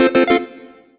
collect_item_chime_02.wav